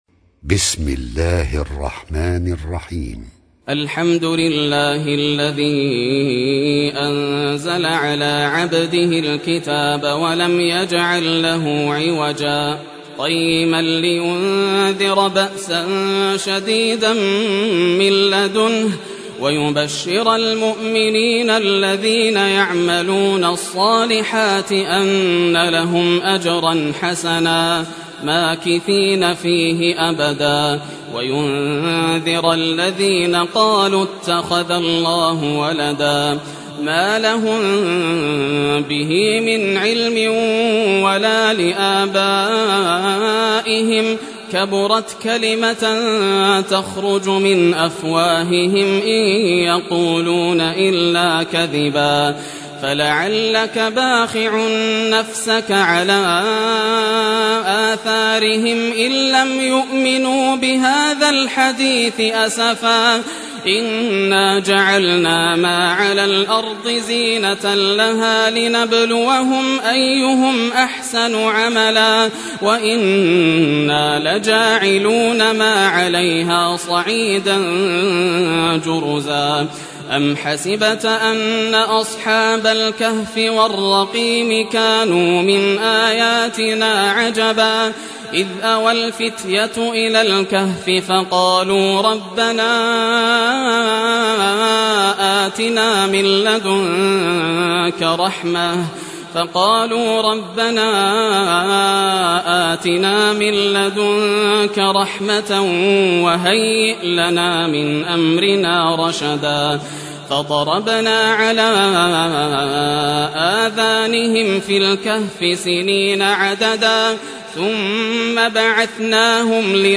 Surah Al Kahf, listen or play online mp3 tilawat / recitation in Arabic in the beautiful voice of Sheikh Yasser Al Dosari.